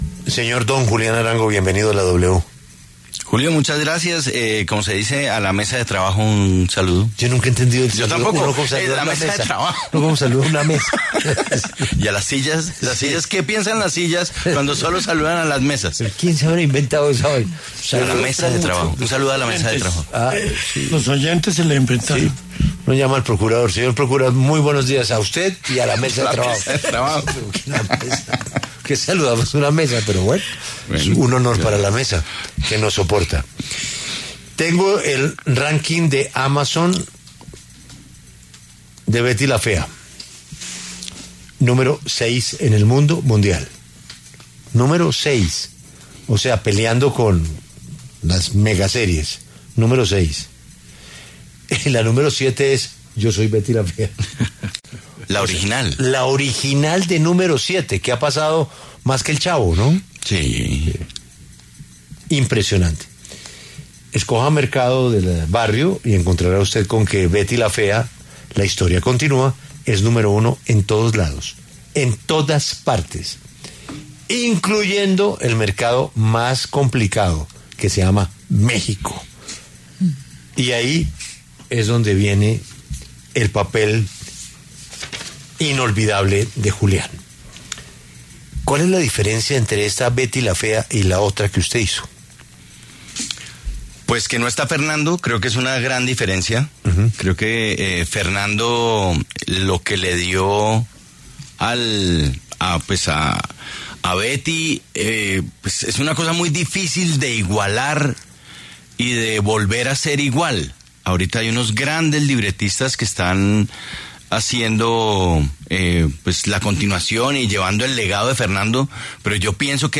El actor Julián Arango pasó por los micrófonos de La W para hablar sobre algunos papeles destacados de su trayectoria como ‘Hugo Lombardi’, ‘Guadaña’ y ‘Evaristo’.